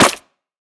Minecraft Version Minecraft Version snapshot Latest Release | Latest Snapshot snapshot / assets / minecraft / sounds / block / muddy_mangrove_roots / step3.ogg Compare With Compare With Latest Release | Latest Snapshot
step3.ogg